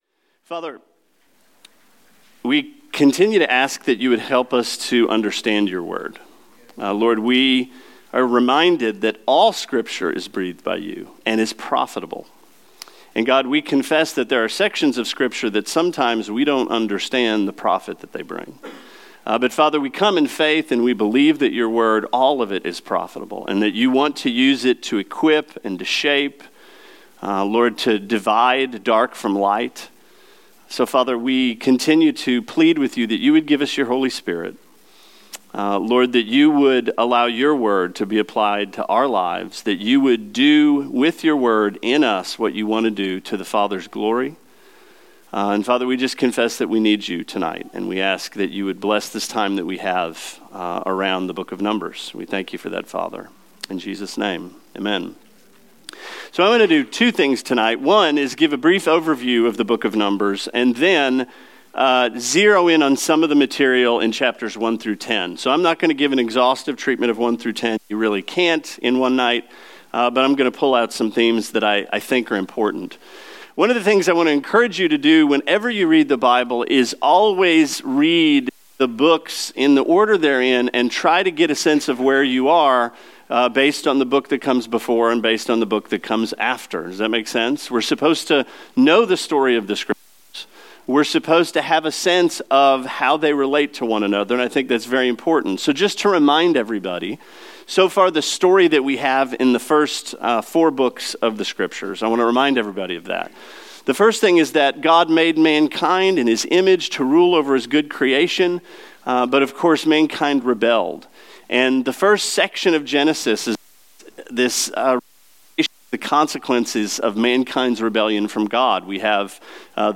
Sermon 09/27: Numbers 1-10